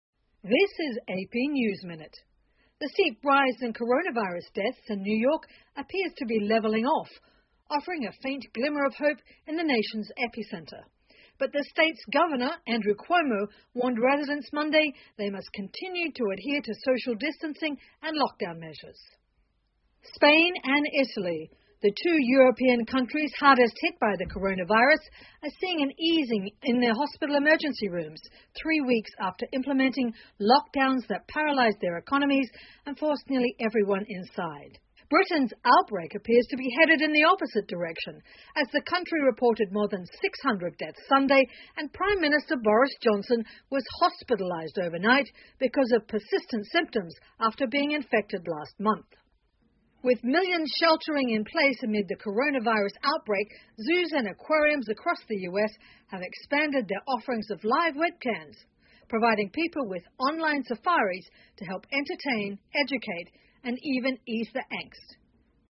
美联社新闻一分钟 AP 英国首相病情恶化入院治疗 听力文件下载—在线英语听力室